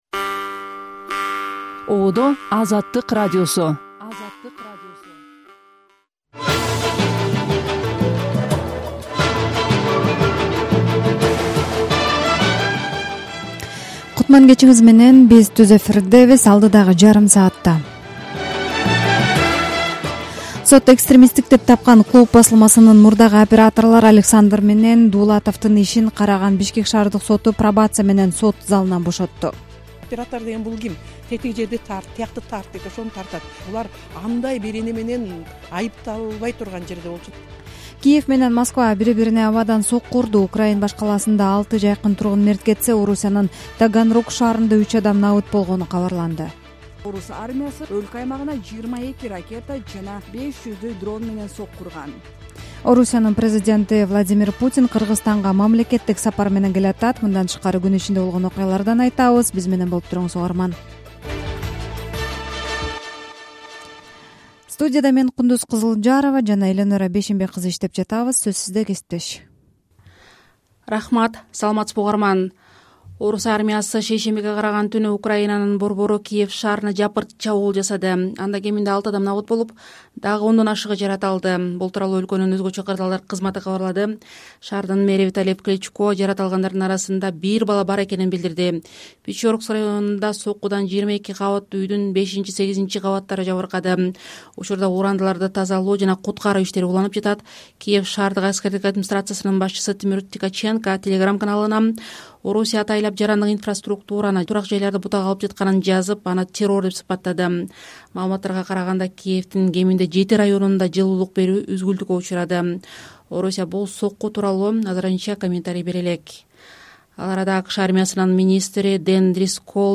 Жаңылыктар | 25.11.2025 | Лейлекте Тажикстан менен алмашылган үйлөрдүн тургундары көчүрүлүүдө